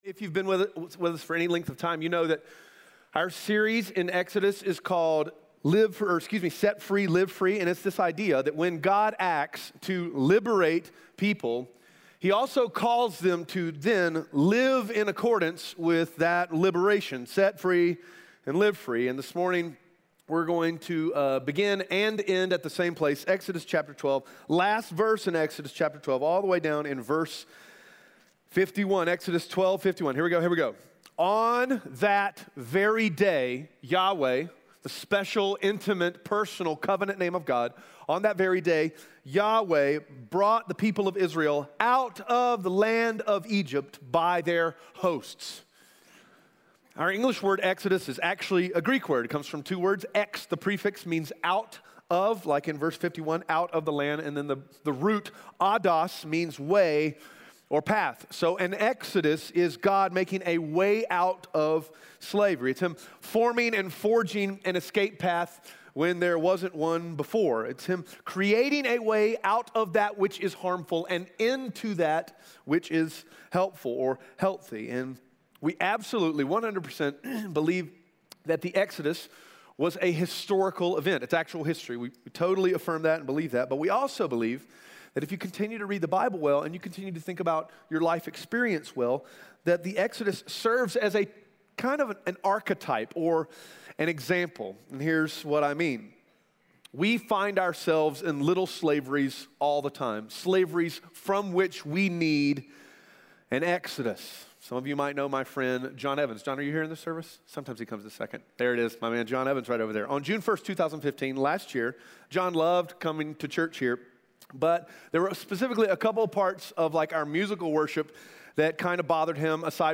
Audio Sermon Notes (PDF) Ask a Question *We are a church located in Greenville, South Carolina.